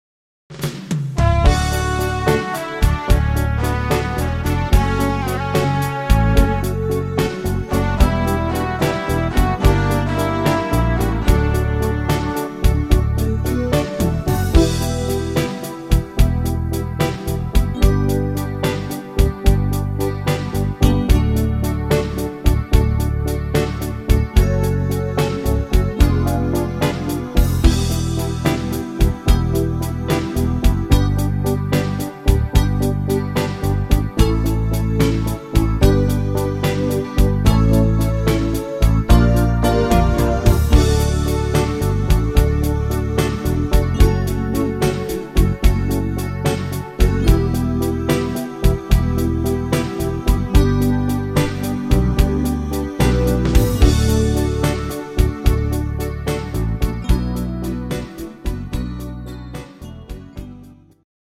ein richtig kerniger Blues